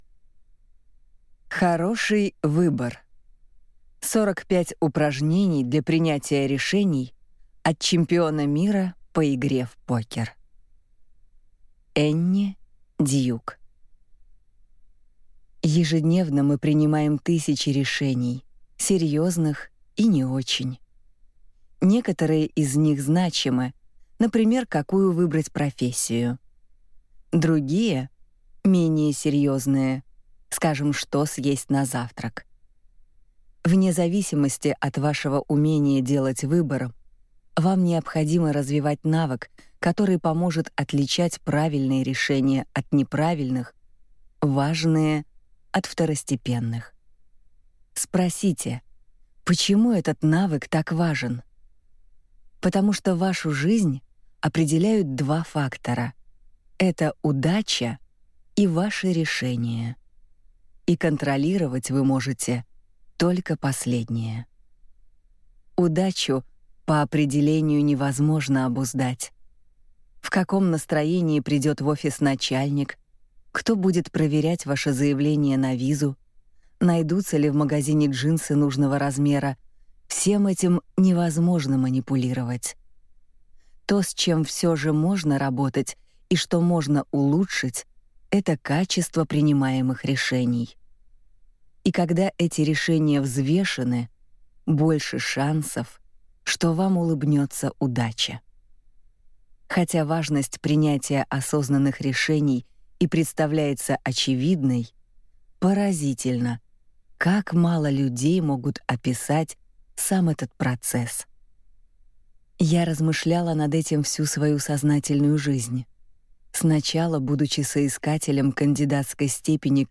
Аудиокнига Хороший выбор. 45 упражнений для принятия решений от чемпиона мира по игре в покер | Библиотека аудиокниг